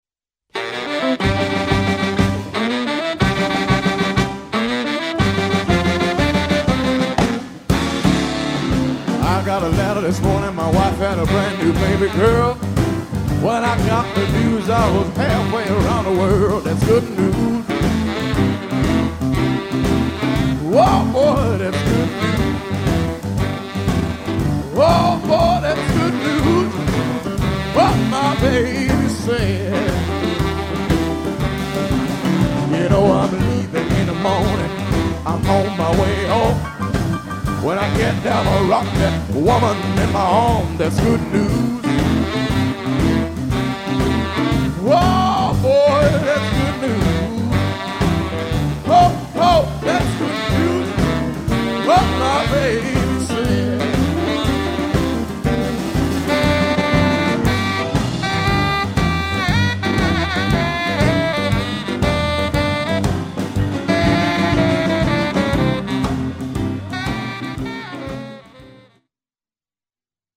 Rhythm‘n Blues
piano, chant
saxo ténor
saxo baryton
guitare
contrebasse
batterie